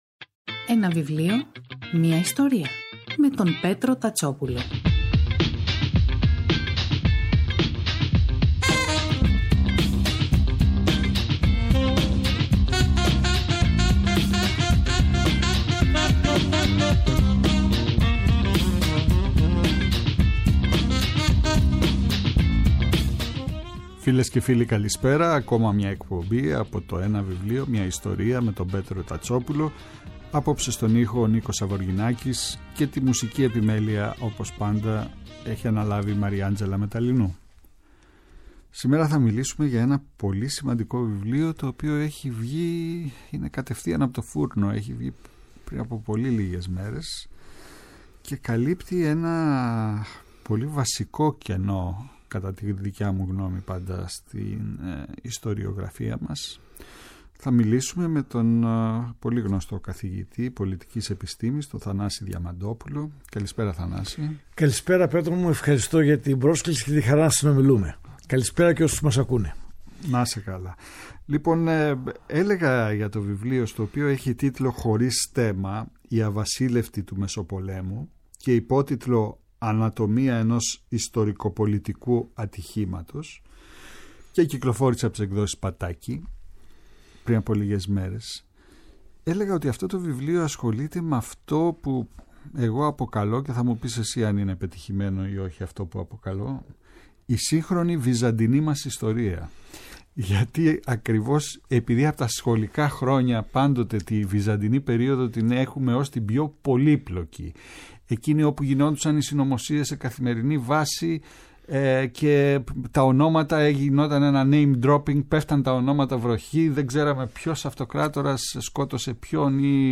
Κάθε Σάββατο και Κυριακή, στις 5 το απόγευμα στο Πρώτο Πρόγραμμα της Ελληνικής Ραδιοφωνίας ο Πέτρος Τατσόπουλος , παρουσιάζει ένα συγγραφικό έργο, με έμφαση στην τρέχουσα εκδοτική παραγωγή, αλλά και παλαιότερες εκδόσεις.